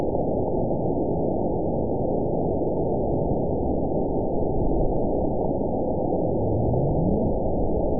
event 919898 date 01/27/24 time 22:21:02 GMT (1 year, 3 months ago) score 9.60 location TSS-AB05 detected by nrw target species NRW annotations +NRW Spectrogram: Frequency (kHz) vs. Time (s) audio not available .wav